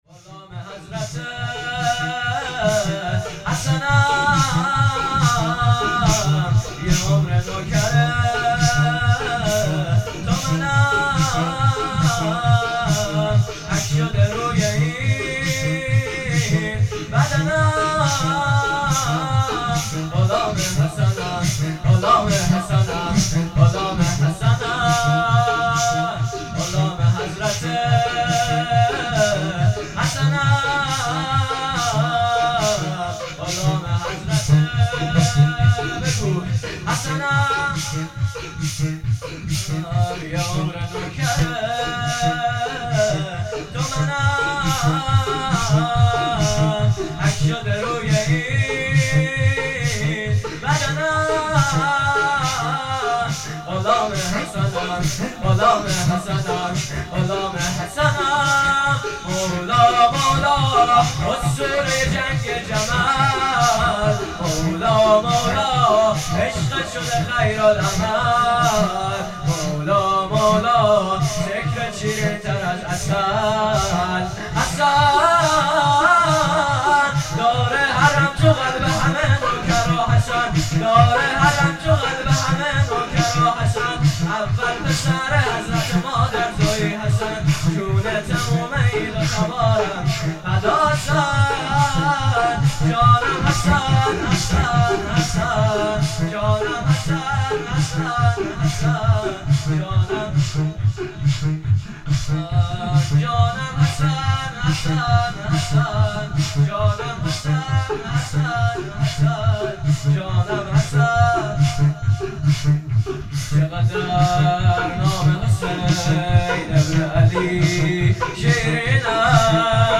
هیئت هفتگی
مکان مسجد امام موسی بن جعفر علیه السلام